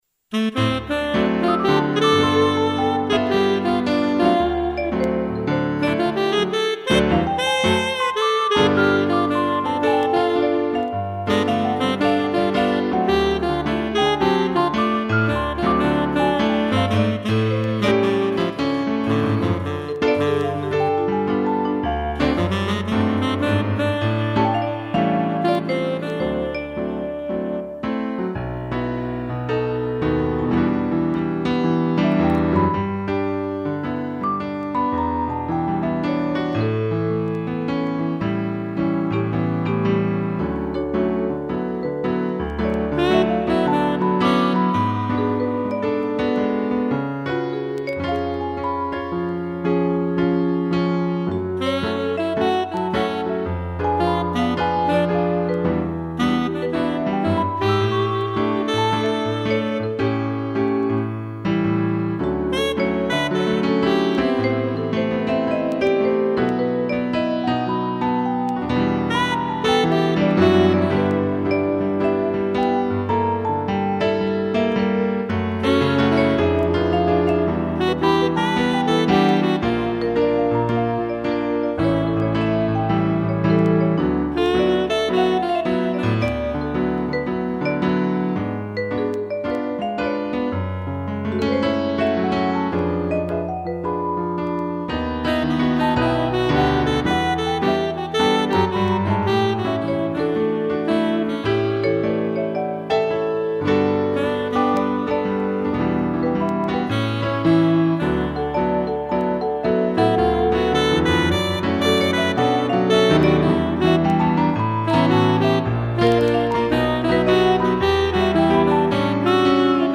piano, vibrafone e sax
instrumental